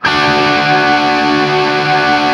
TRIAD D  L-R.wav